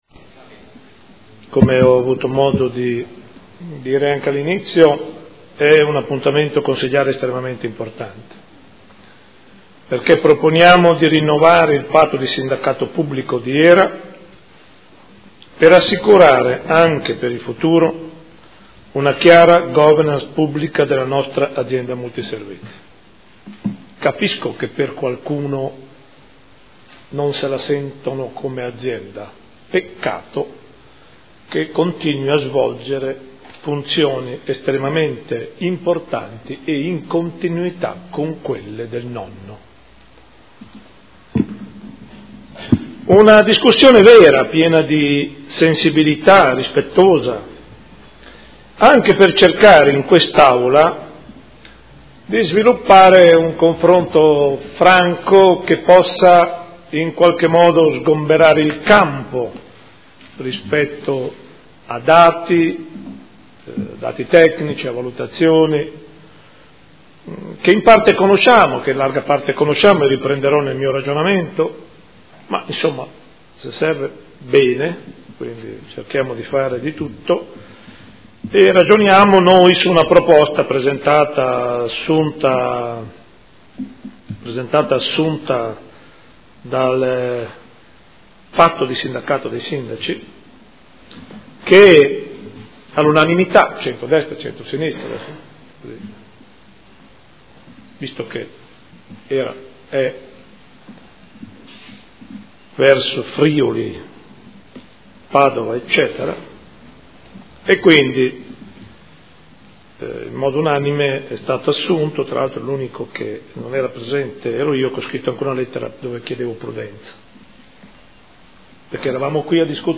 Sindaco — Sito Audio Consiglio Comunale
Seduta del 16/04/2015.